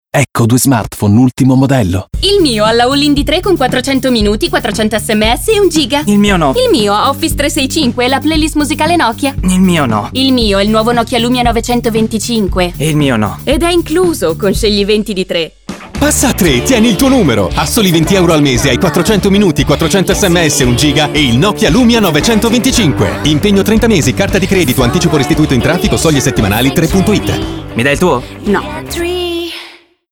On air il nuovo spot radiofonico 3 Italia, protagonista il Nokia Lumia 925
Nello spot una ragazza e un ragazzo mettono a confronto i loro smartphone ultimo modello: lei ha “…la ALL-IN Medium di 3 con 400 minuti, 400 SMS e 1 Giga”, lui no. Lei ha “…Microsoft Office 365 e l’esclusiva playlist musicale Nokia!”, lui no. Lei ha “…il nuovo Nokia Lumia 925” – “Ed è incluso, con Scegli 20 di 3!”.